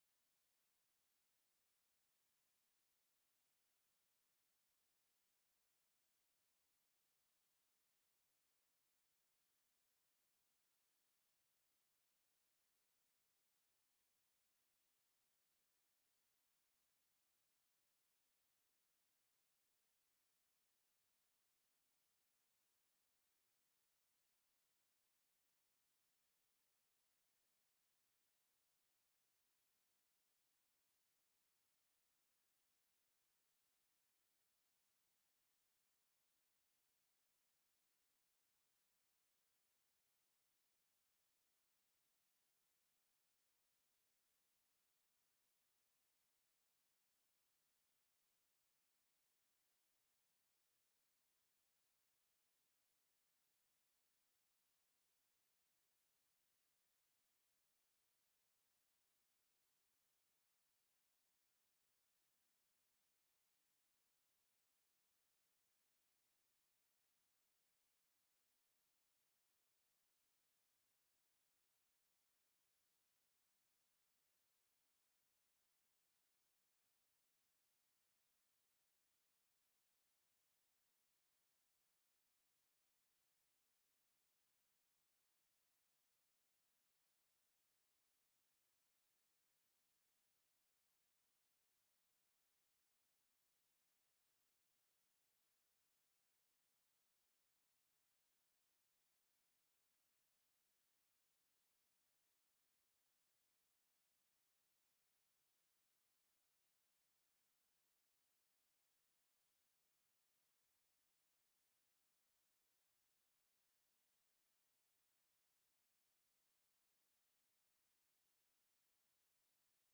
The 29th of September 2024 Praise and Worship